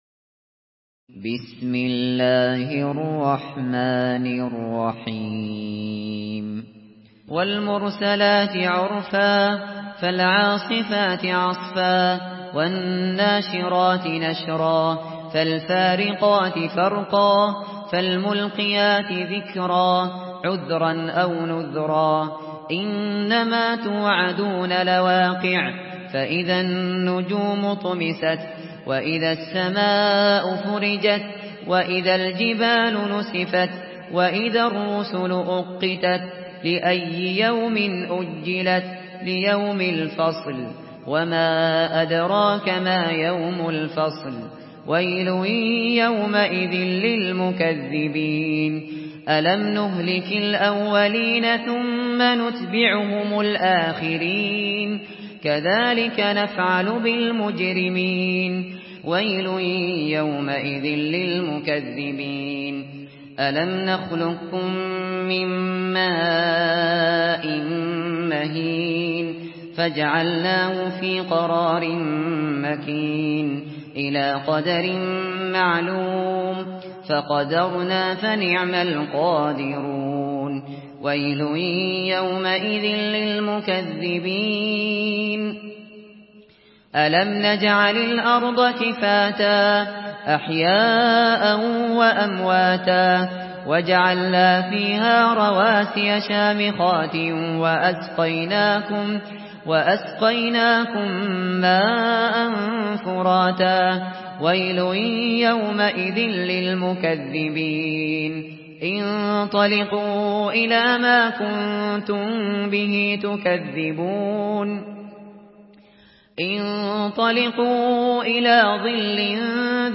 Surah আল-মুরসালাত MP3 in the Voice of Abu Bakr Al Shatri in Hafs Narration
Murattal Hafs An Asim